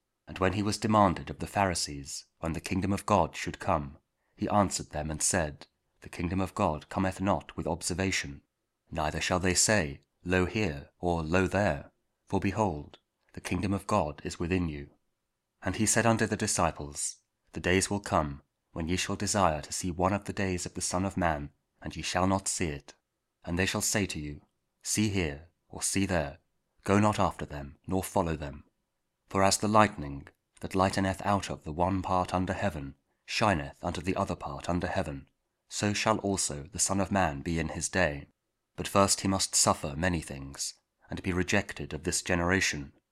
Luke 17: 20-25 – Week 32 Ordinary Time, Thursday (King James Audio Bible KJV, Spoken Word)